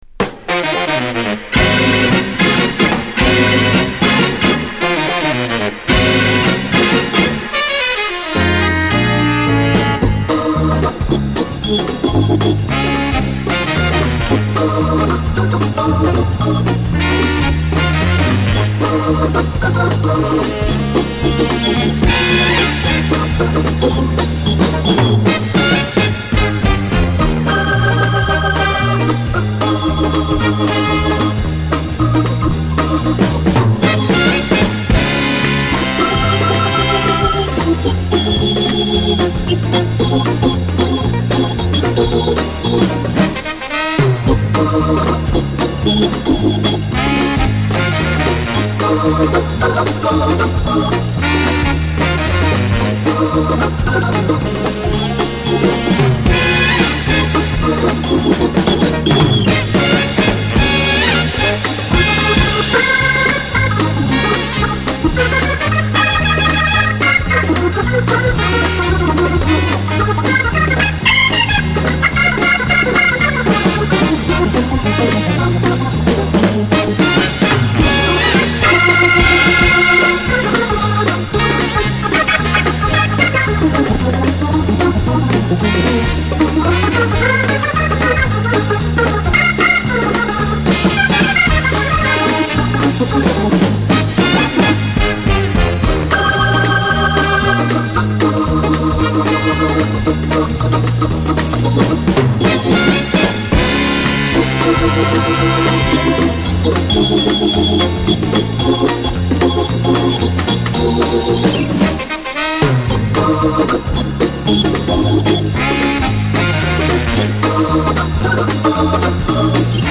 スウェーデンの名オルガン奏者
drum
guitar